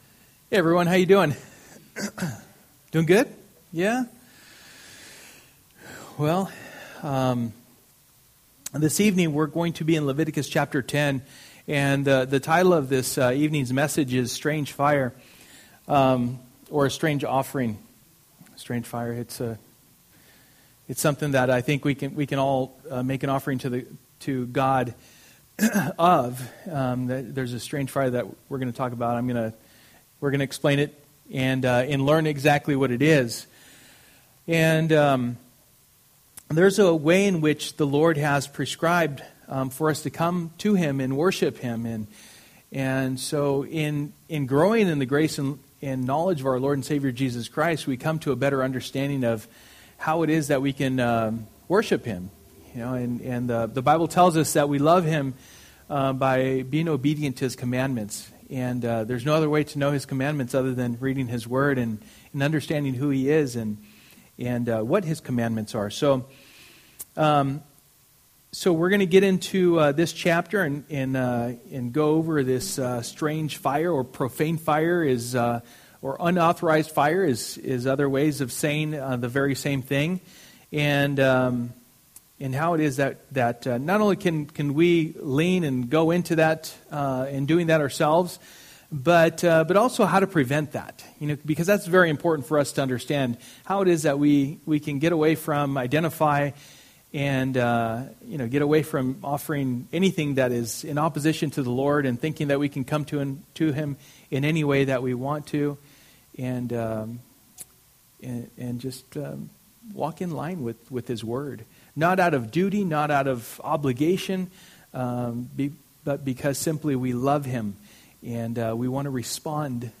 Through the Bible Passage: Leviticus 10:1-20 Service: Wednesday Night %todo_render% « Excelling in Generosity